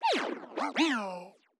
BallReset.wav